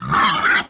Index of /App/sound/monster/wild_boar_god
fall_1.wav